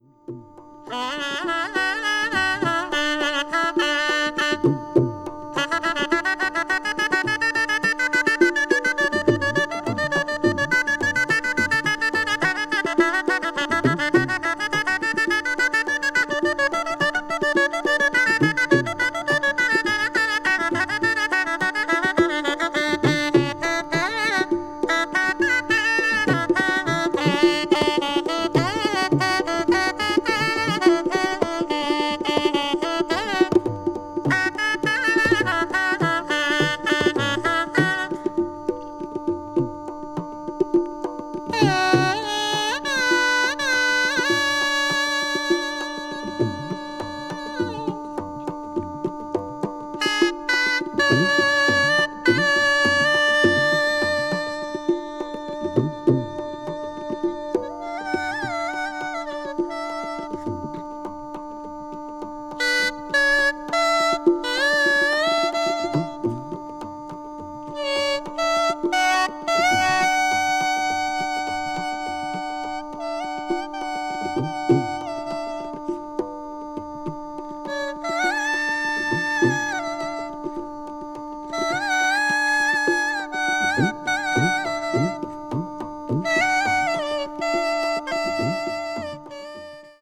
No. 19 Shahnai Raga Yaman Tala Tintala
classical   ethnic music   india   oriental   traditional